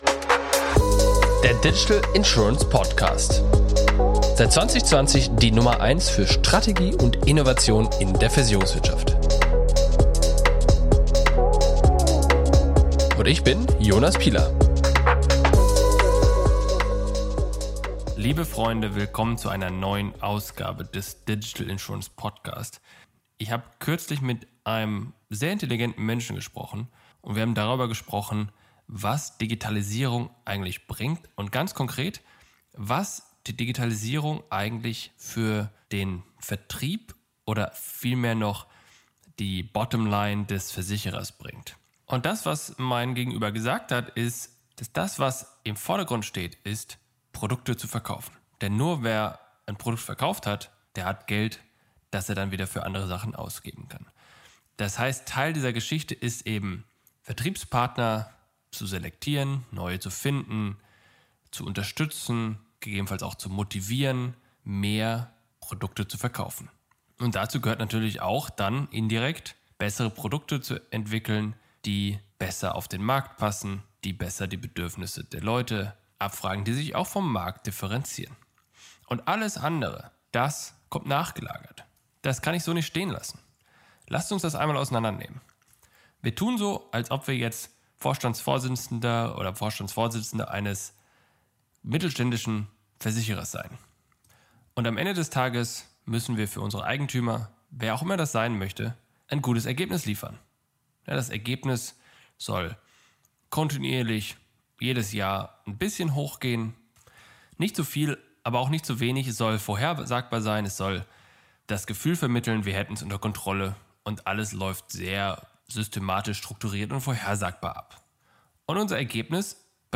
monolog